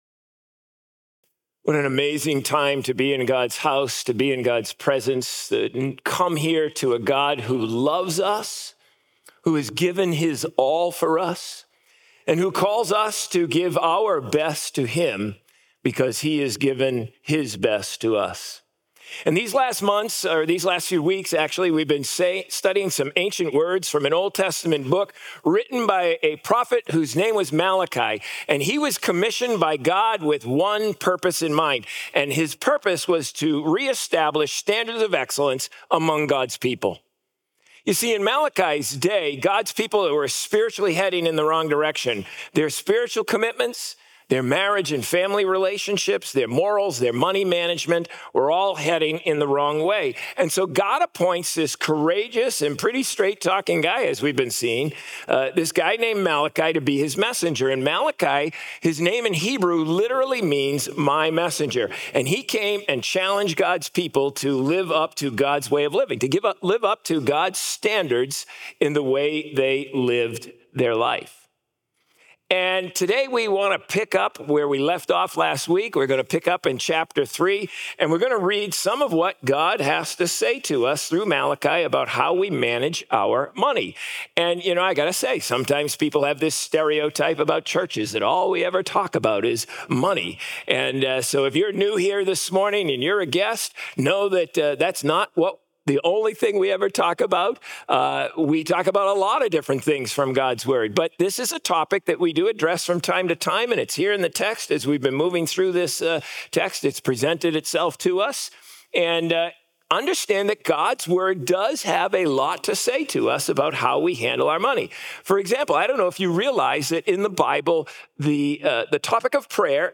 Sermons | COMMUNITY Covenant Church